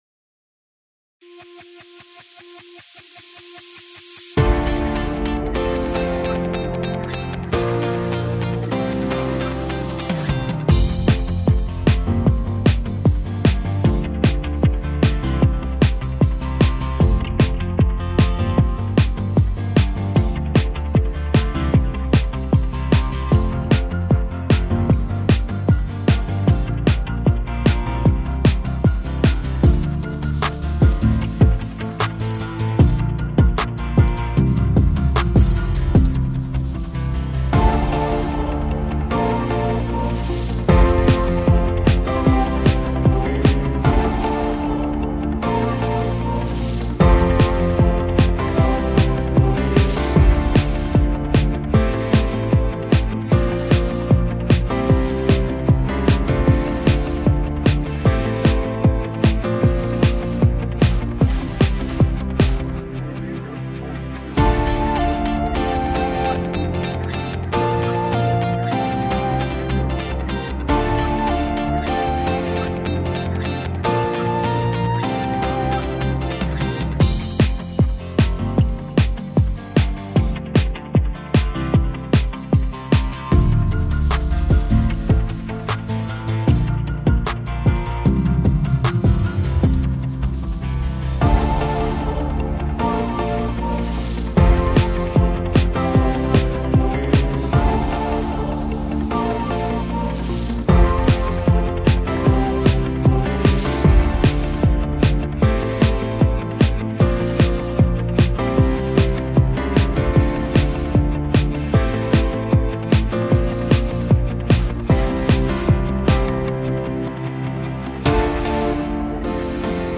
MADE-IN-ASSISI-BASE-no-voci.mp3